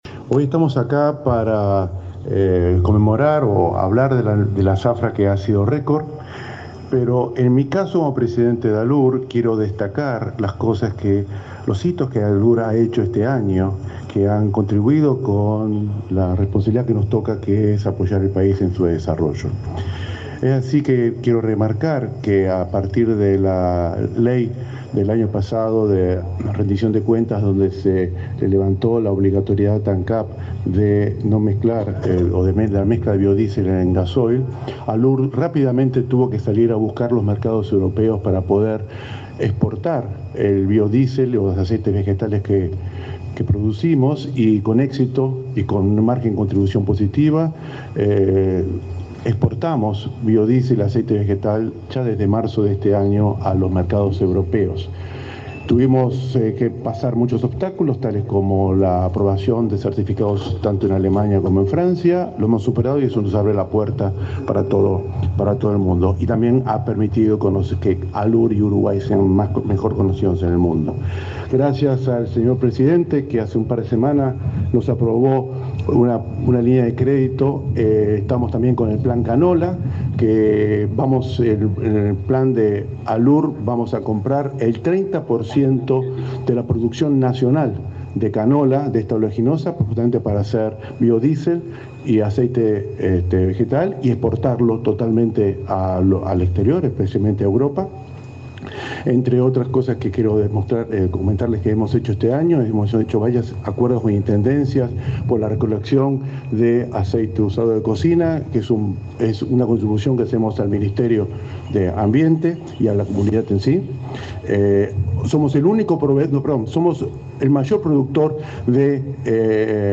Palabras de autoridades en acto por el fin de zafra de caña de azúcar
Palabras de autoridades en acto por el fin de zafra de caña de azúcar 11/11/2022 Compartir Facebook X Copiar enlace WhatsApp LinkedIn El presidente de Alcoholes del Uruguay (ALUR), Alfredo Fernández; el titular de Ancap, Alejandro Stipanicic; el ministro de Industria, Omar Paganini, y el subsecretario de Ganadería, Ignacio Buffa, participaron en Artigas en el acto por el fin de la zafra de la caña de azúcar.